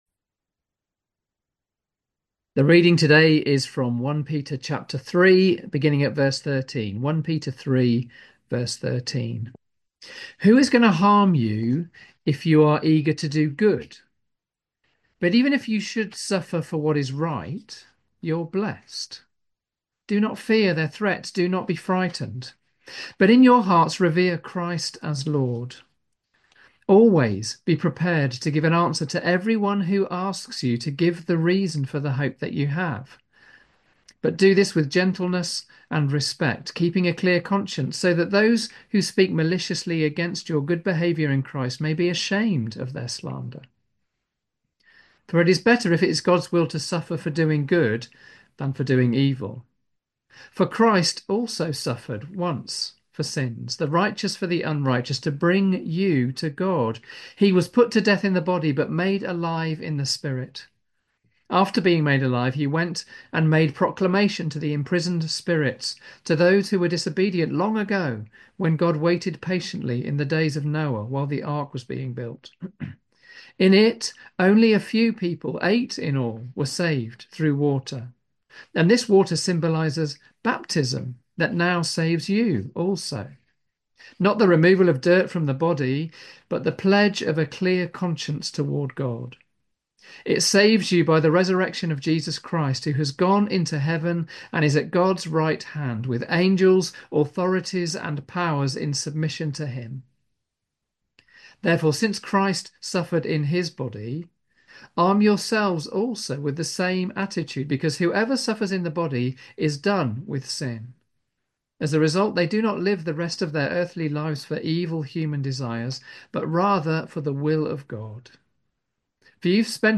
Sunday Service
1 Peter: Confidence in a Complex World Theme: Confident in Witness Sermon